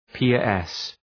Προφορά
{‘pıərıs}